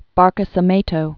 (bärkə-sə-mātō, -kē-sē-mĕ-)